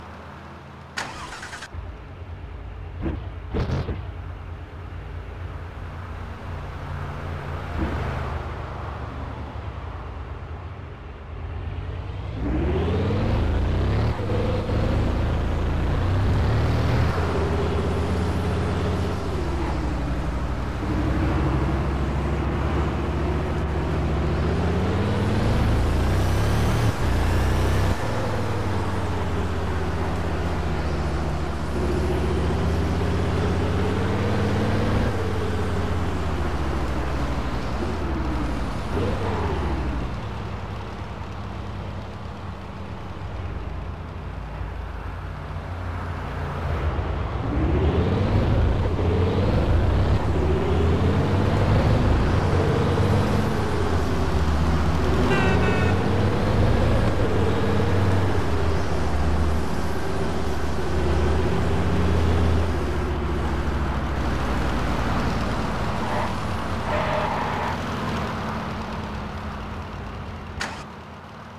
- Bugatti Chiron